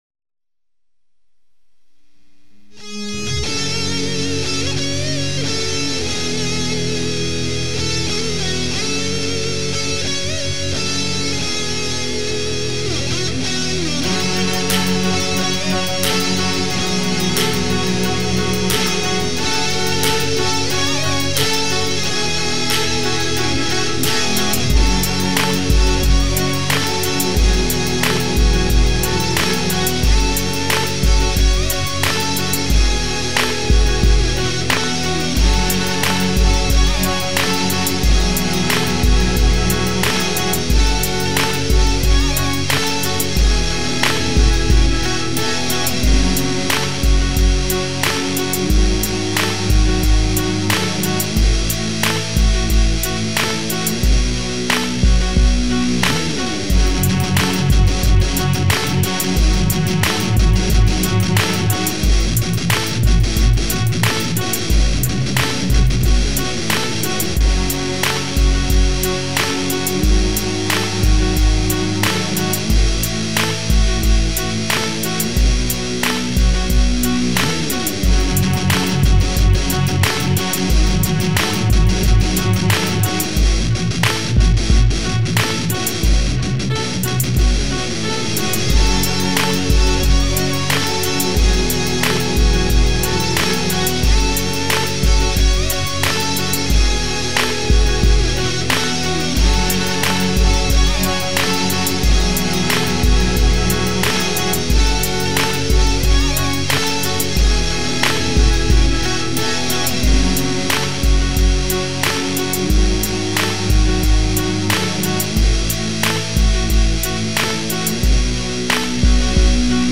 Just a phat beet